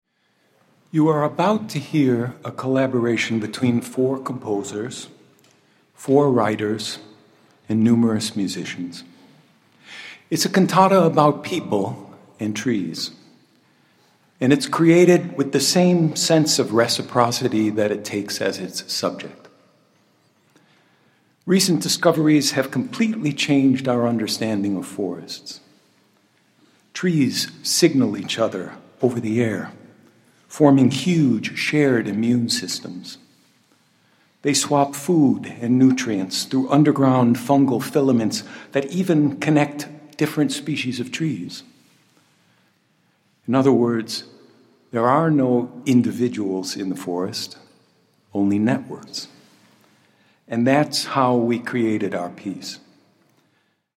Richard Powers, narrator1:52
Recorded April 15, 2023 at the University of South Carolina School of Music